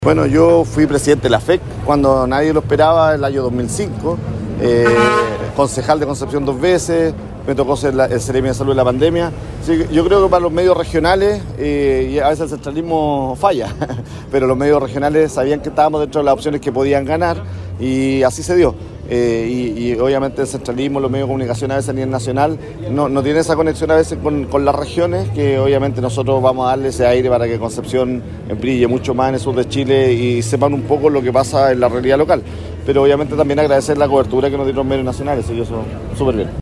Entre aplausos y felicitaciones llegó a un céntrico café penquista el alcalde electo por la comuna de Concepción, Héctor Muñoz, para dialogar con la prensa en las horas posteriores a su triunfo electoral.